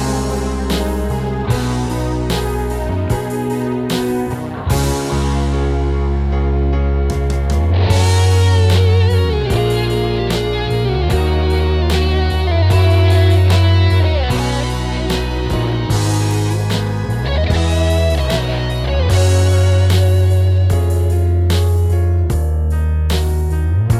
One Semitone Down Rock 3:47 Buy £1.50